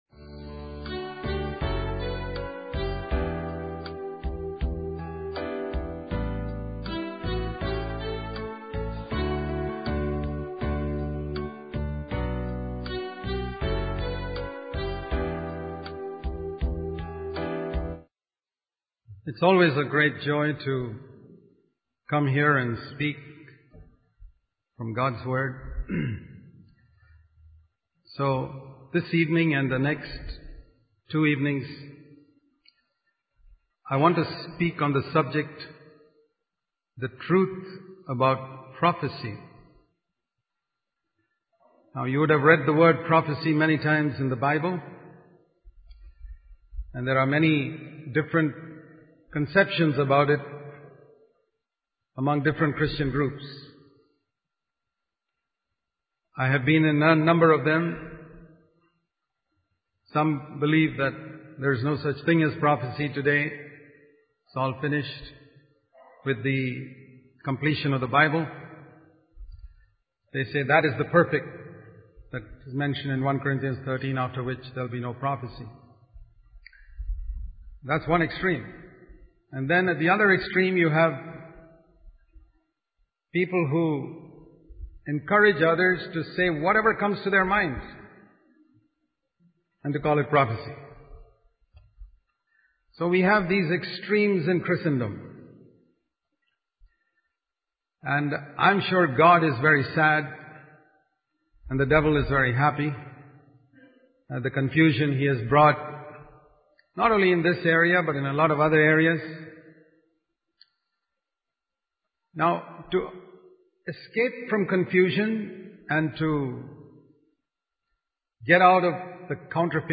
In this sermon, the preacher discusses the potential consequences of allowing certain types of music into our lives.